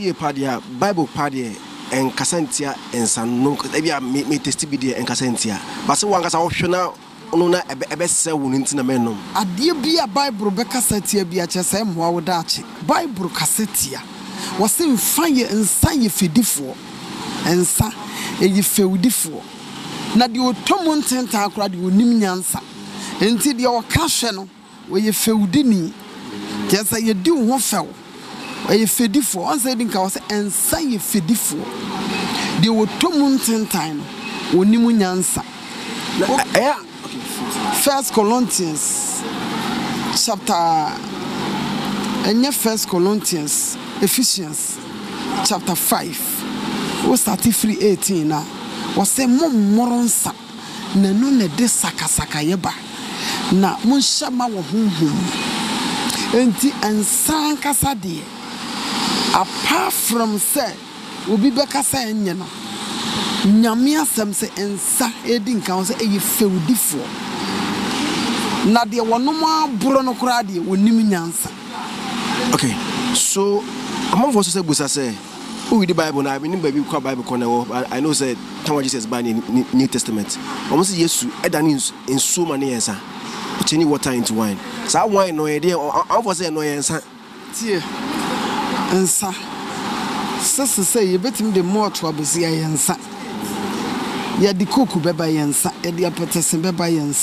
This remark was a reply she gave to a question about whether or not the drinking of alcohol was something the Bible speaks against when she was recently interviewed on the ‘Zionfelix Uncut’ show during the launch of the Kumasi edition of the Abba Father Concert, 2019.